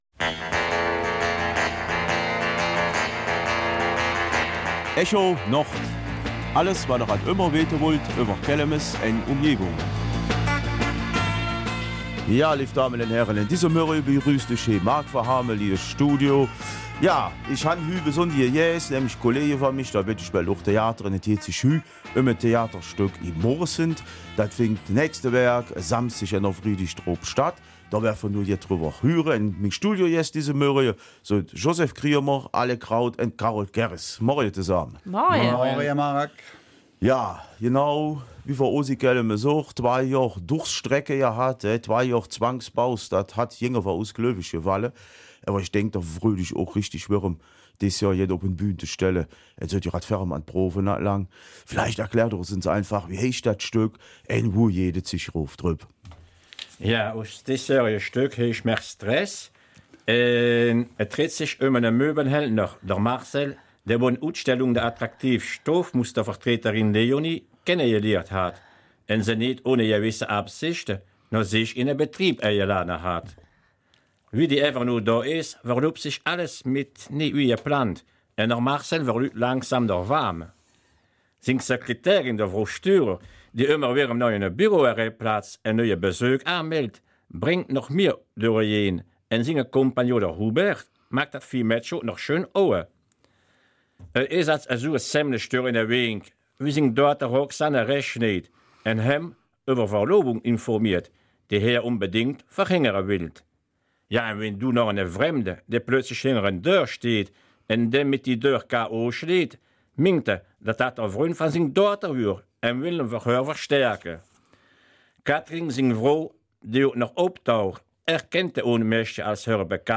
Kelmiser Mundart: Theater in Moresnet